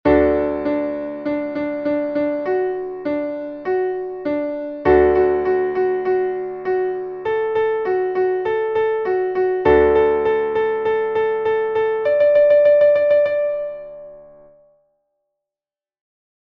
Traditionelles Kinderlied (Kanon)